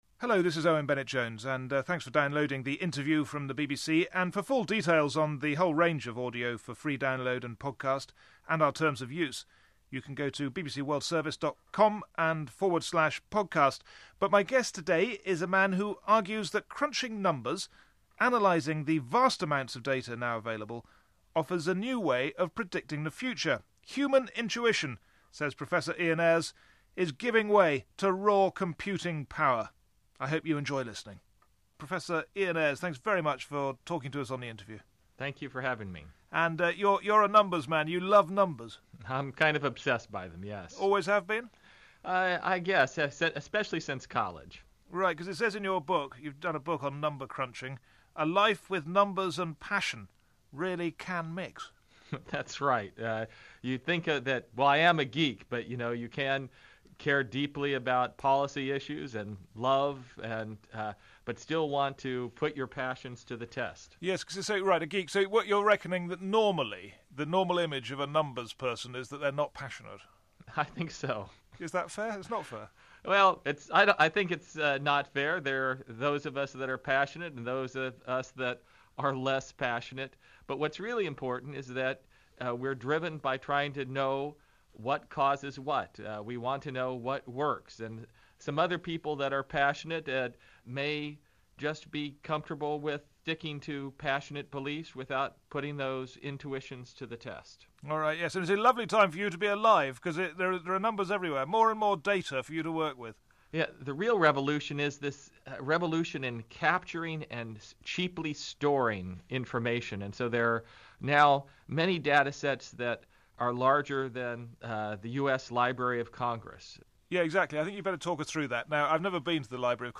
Crunching numbers - BBC World Service Interview